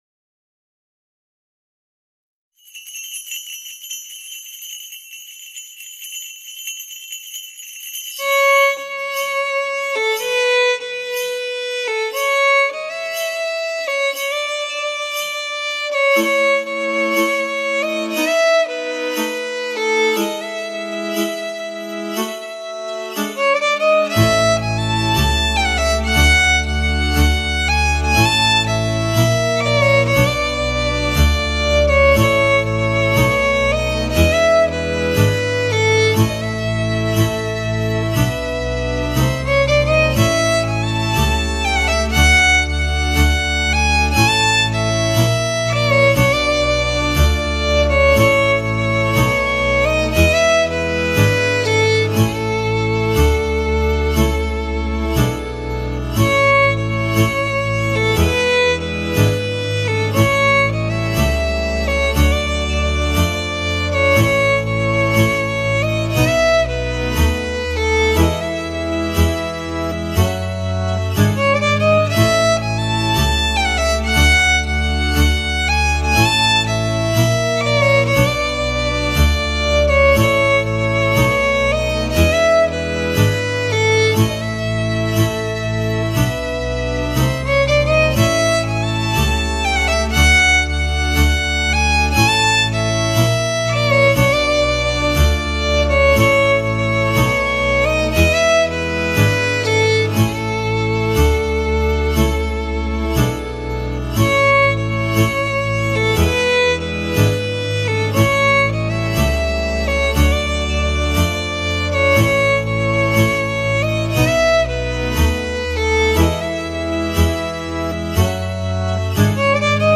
Data: 13.10.2024  Colinde Craciun Hits: 0